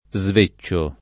vai all'elenco alfabetico delle voci ingrandisci il carattere 100% rimpicciolisci il carattere stampa invia tramite posta elettronica codividi su Facebook svecciare v.; sveccio [ @ v %©© o ], -ci — fut. sveccerò [ @ ve ©© er 0+ ]